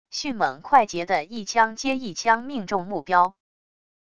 迅猛快捷的一枪接一枪命中目标wav音频